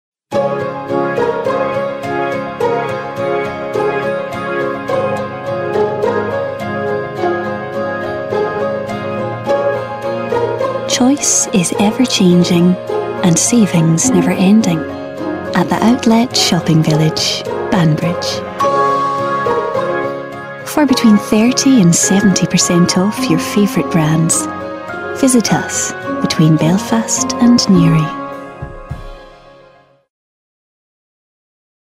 SCOTS. Smooth and calming to upbeat irreverence. Personable lass. A natural VO. Avid Muso.
Her accents range from Scottish East Coast, Edinburgh and Highlands to RP and Irish, and voice age ranging from teens to late 20s.